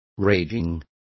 Also find out how furiosa is pronounced correctly.